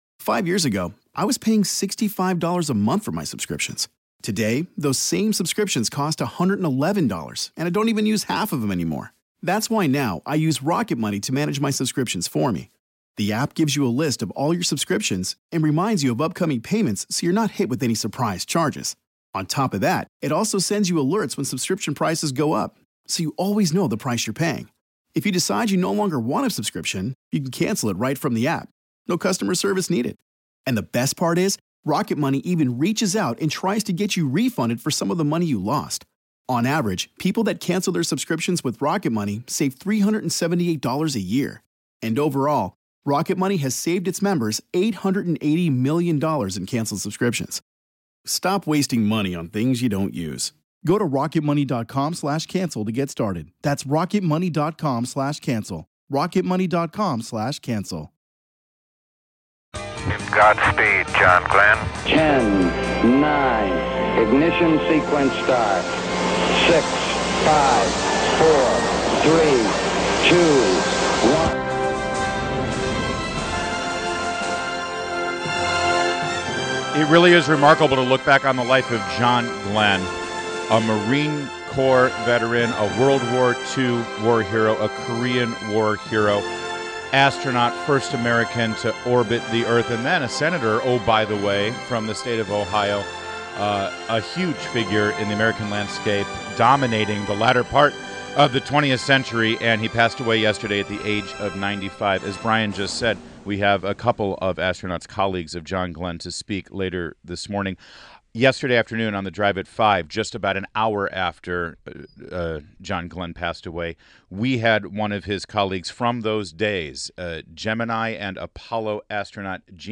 WMAL Interview - GENE CERNAN - 12.09.16